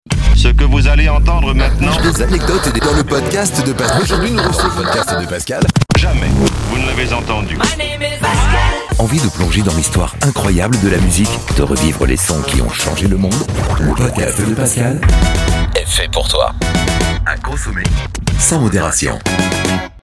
Un jingle promo est disponible en
•  Interventions vocales : oui